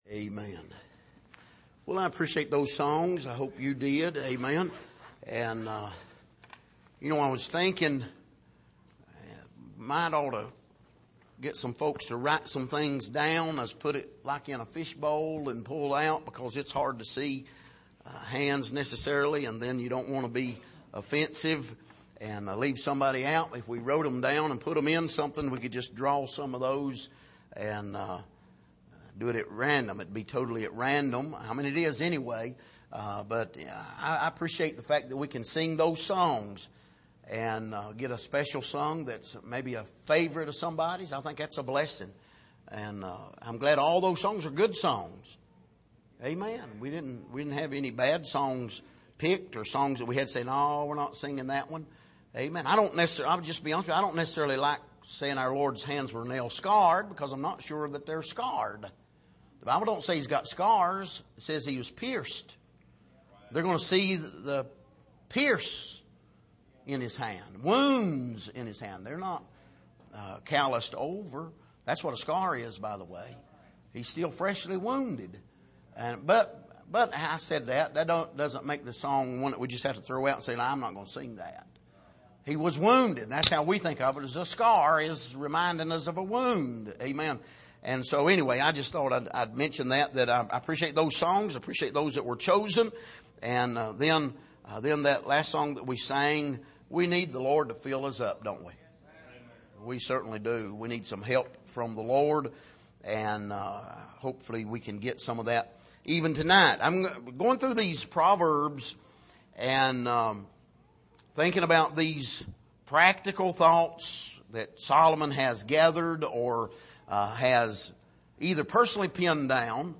Passage: Proverbs 25:15-20 Service: Sunday Evening